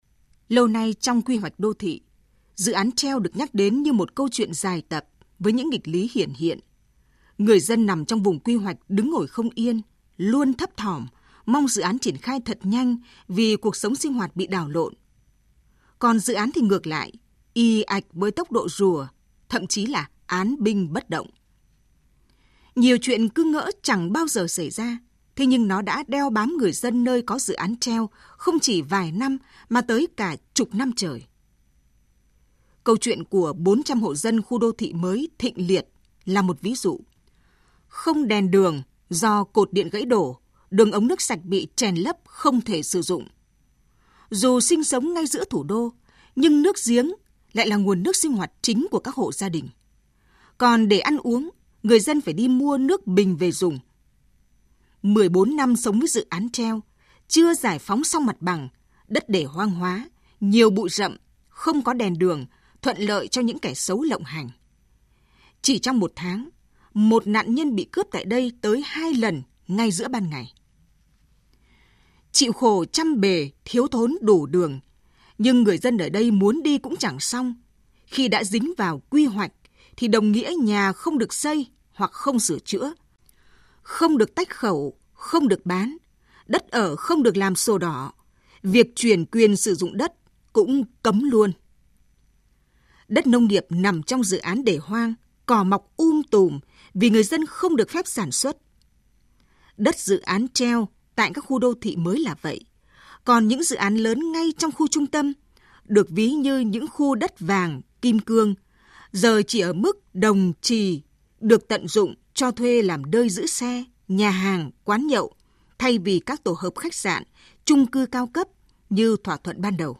THỜI SỰ Bình luận VOV1